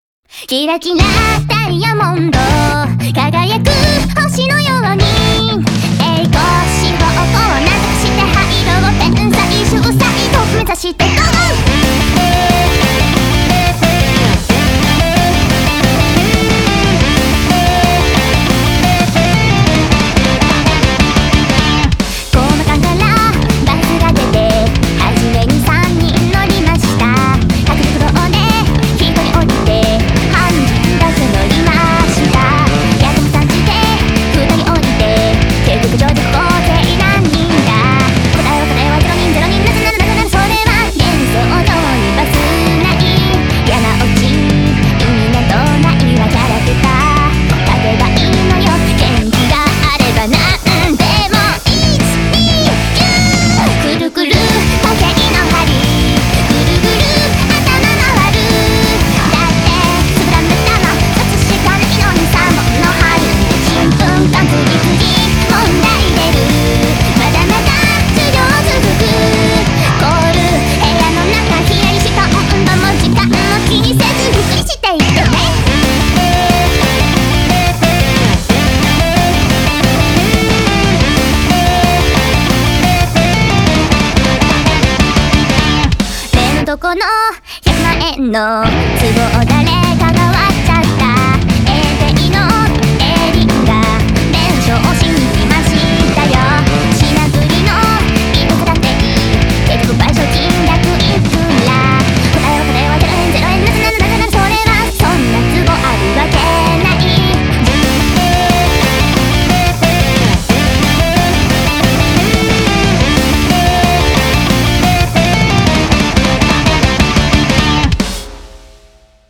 BPM180--1
Audio QualityMusic Cut